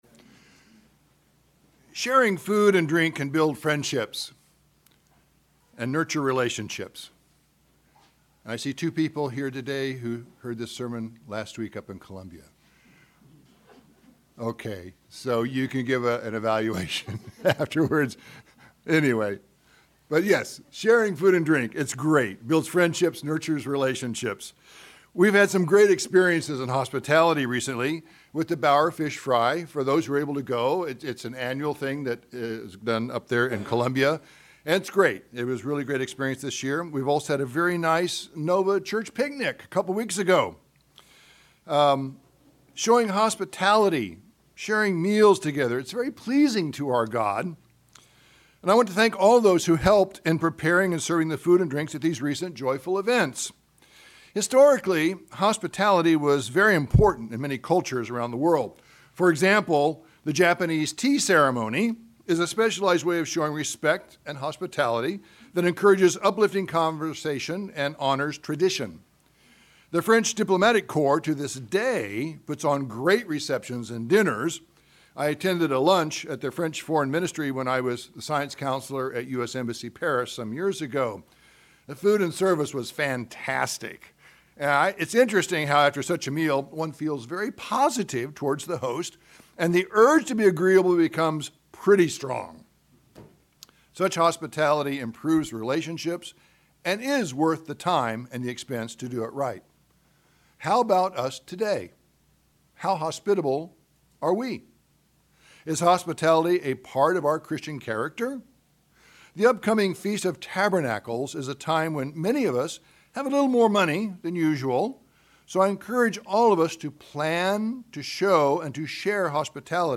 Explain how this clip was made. Given in Northern Virginia